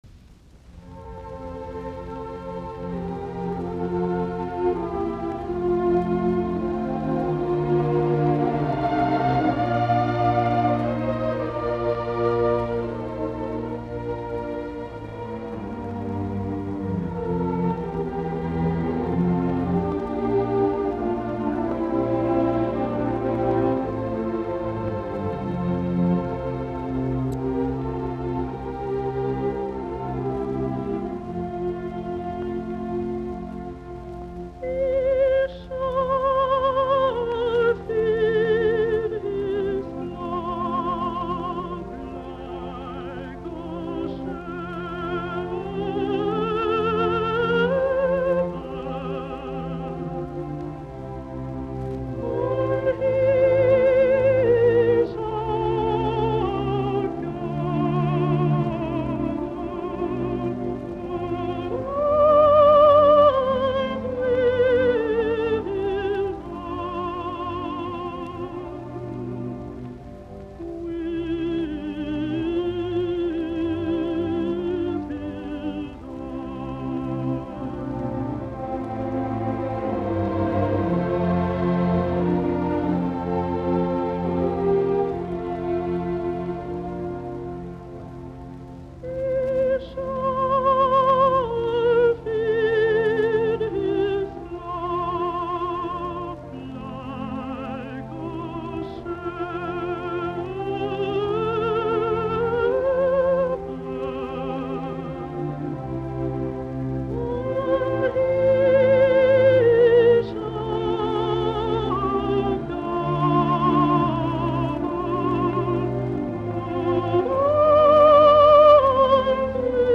musiikkiäänite
altto